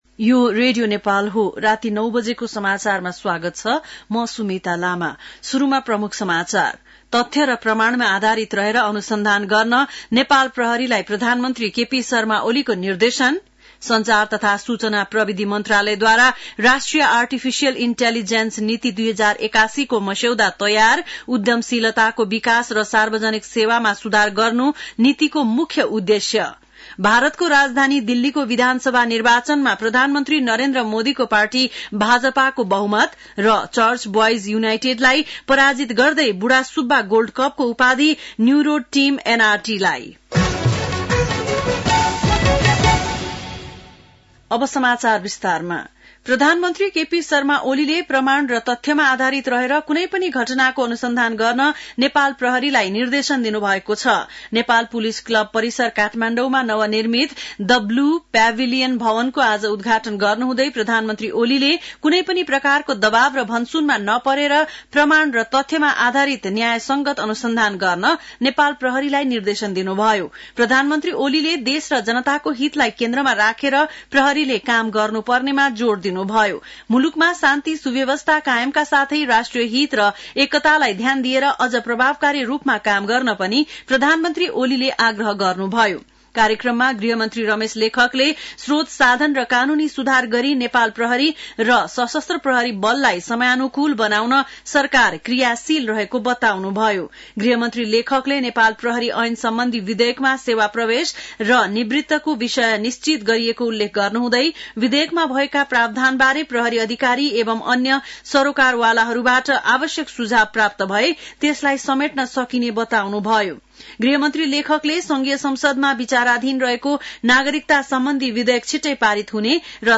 बेलुकी ९ बजेको नेपाली समाचार : २७ माघ , २०८१
9-pm-nepali-news.mp3